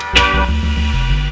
RIFFGTRFX1-R.wav